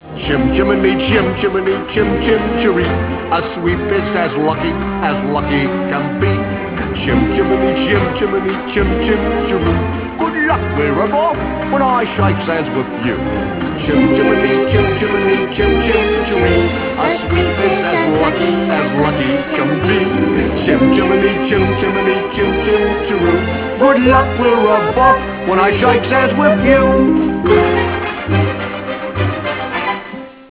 Musical
Original track music